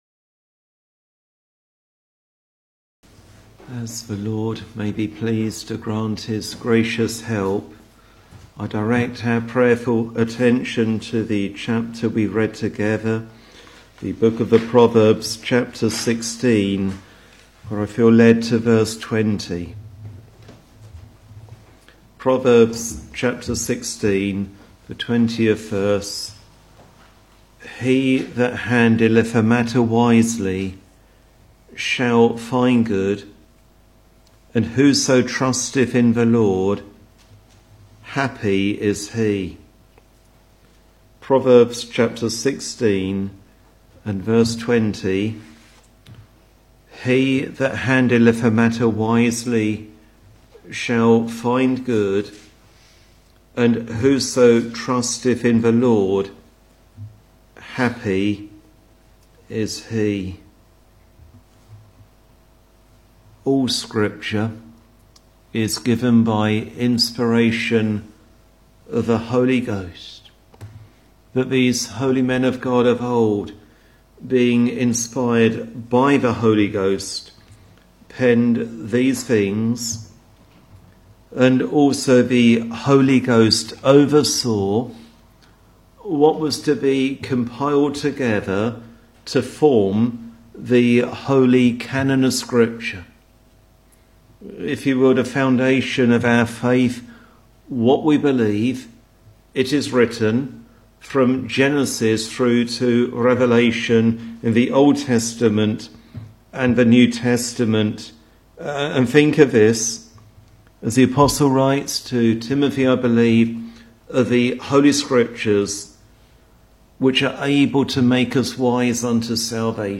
Sermons Proverbs Ch.16 v.20 He that handleth a matter wisely shall find good: and whoso trusteth in the LORD, happy is he.